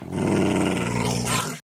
wolf / growl3.ogg
growl3.ogg